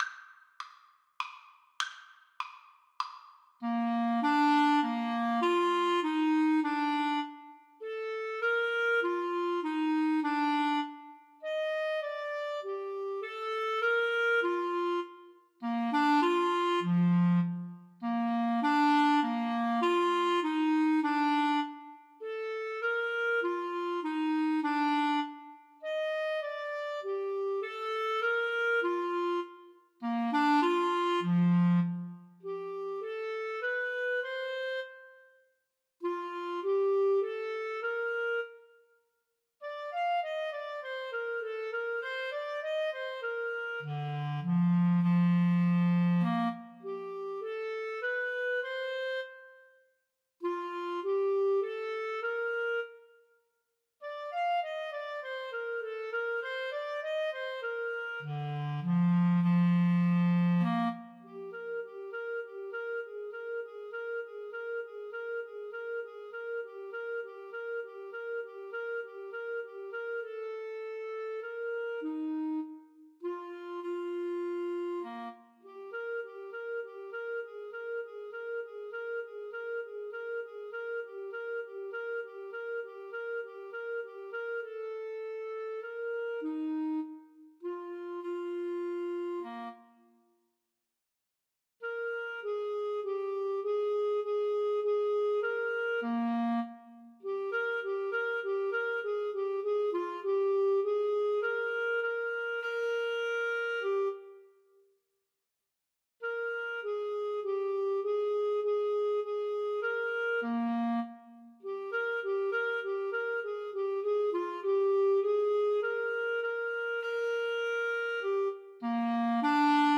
3/4 (View more 3/4 Music)
Clarinet Duet  (View more Easy Clarinet Duet Music)
Classical (View more Classical Clarinet Duet Music)